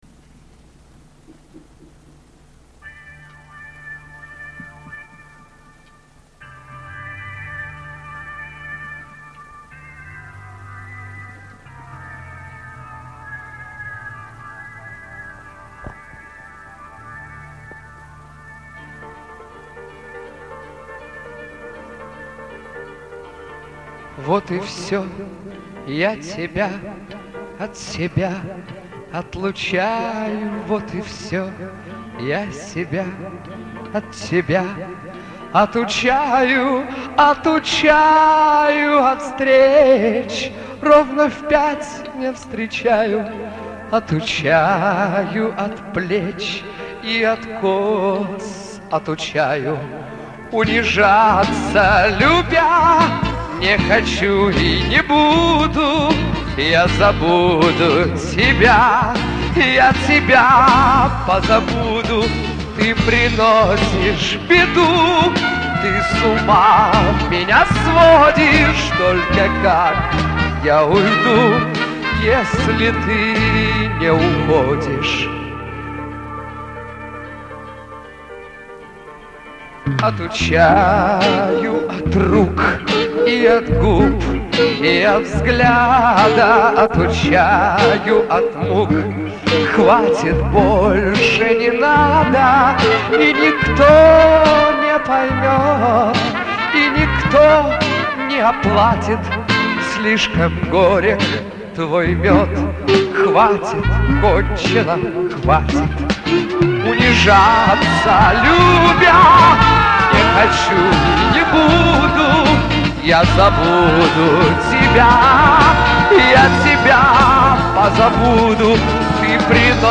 запись с концерта